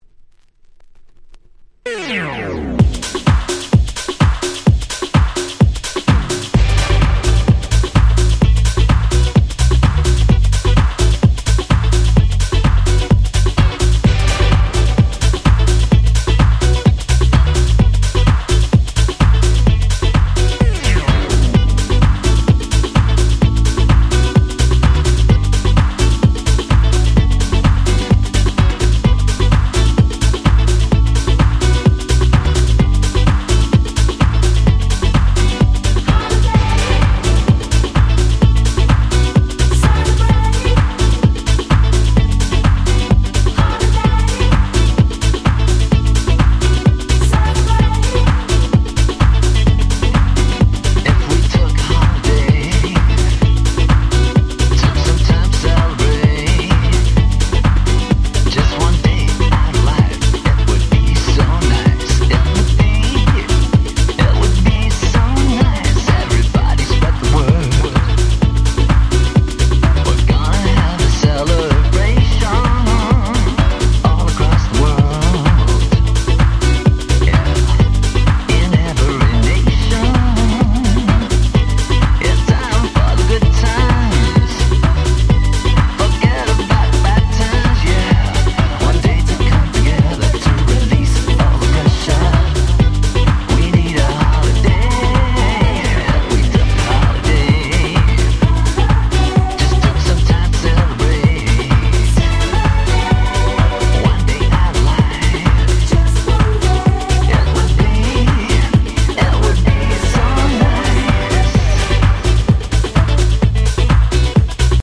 【Media】Vinyl 12'' Single (Promo)
00' Nice Cover Dance Pop !!